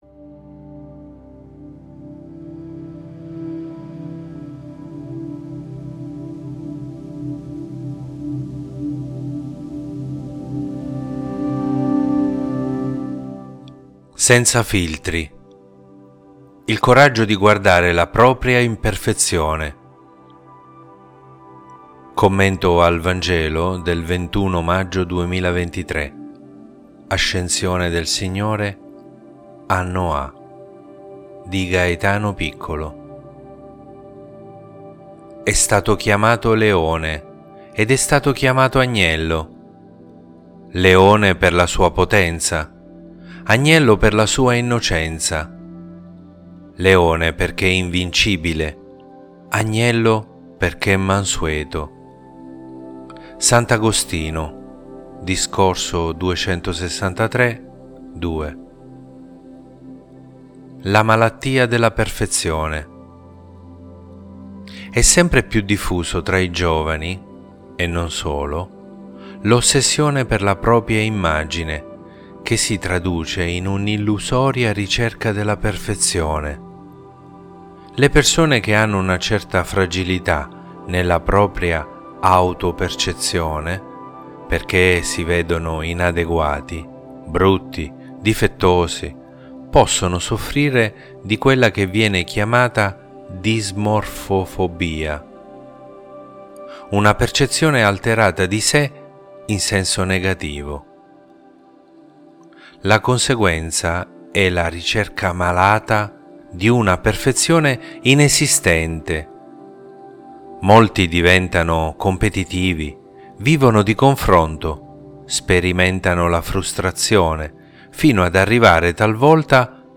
Commento al Vangelo del 21 maggio 2023